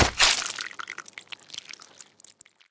claw2.ogg